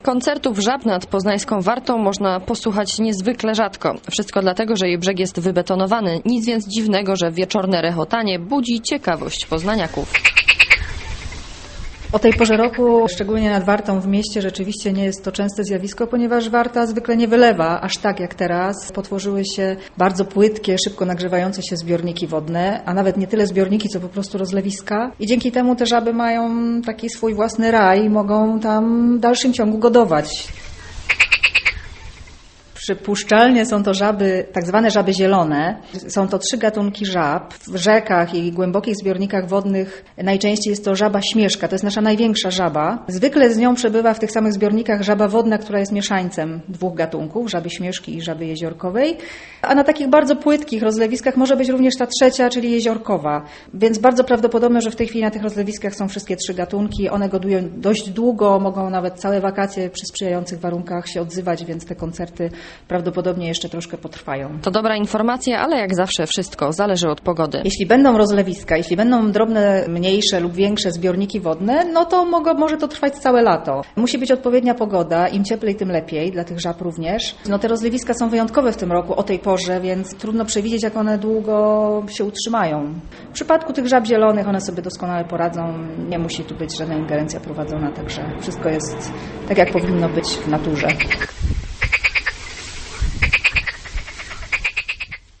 Żaby śpiewają w Warcie
Wysokie temperatury i rozlewiska w terasie zalewowej to sprzyjające warunki dla żab. Nad rzeką wieczorem można posłuchać godowego rechotu.
mc4rhyi8a6hb21g_zaby_nad_warta.mp3